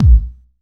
• Low Kick B Key 513.wav
Royality free kick single hit tuned to the B note. Loudest frequency: 101Hz
low-kick-b-key-513-gJc.wav